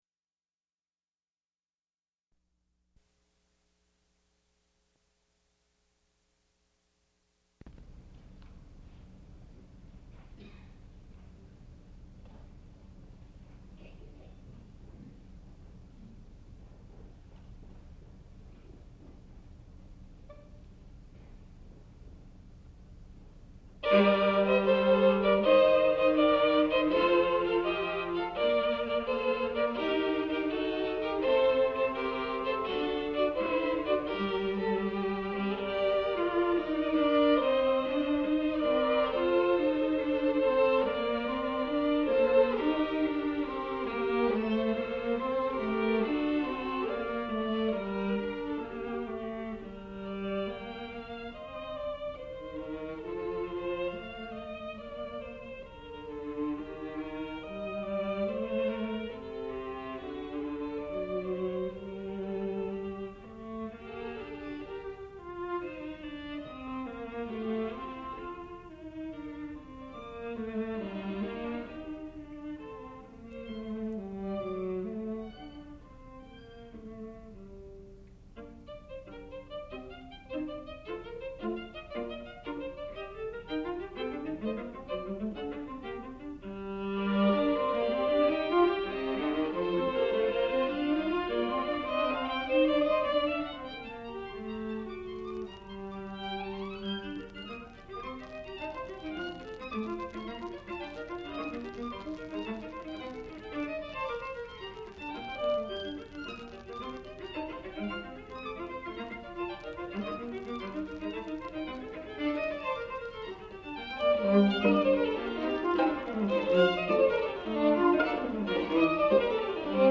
sound recording-musical
classical music
Violin
Viola